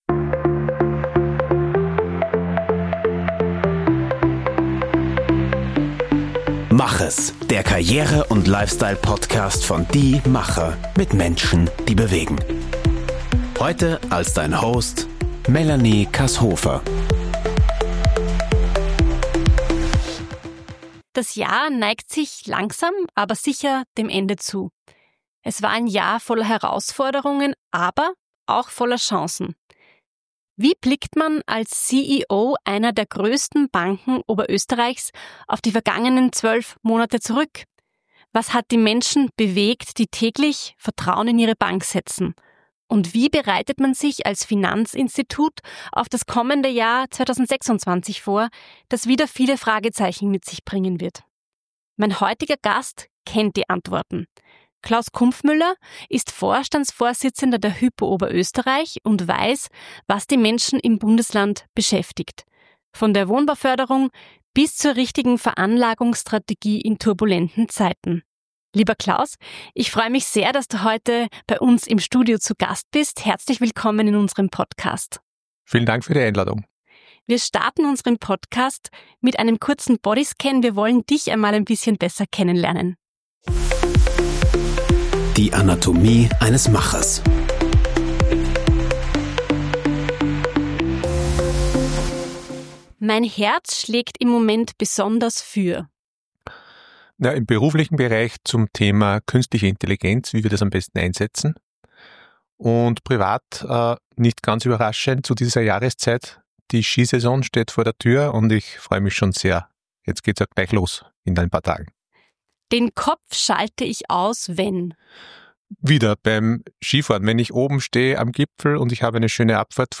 Im Interview erfahren wir außerdem: